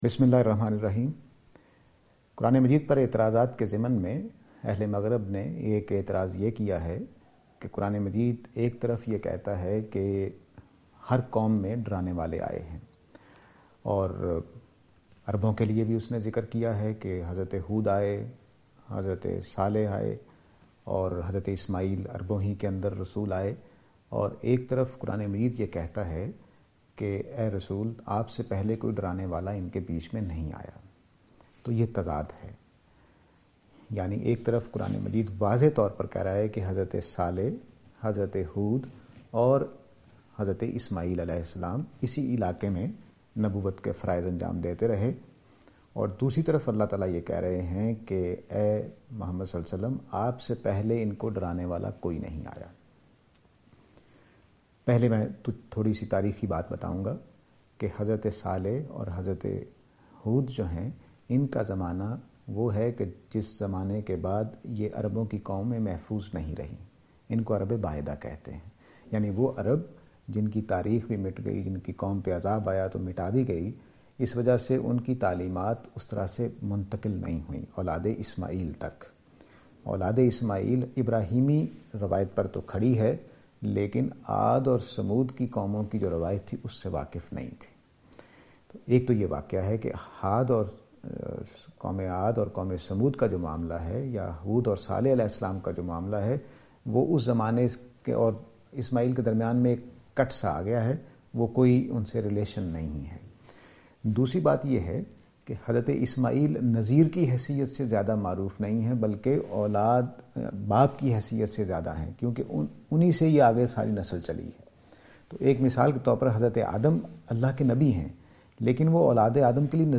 This lecture will present and answer to the allegation "Were any prophets sent to Arabs before Prophet (P B U H)?".